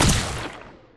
poly_explosion_bio.wav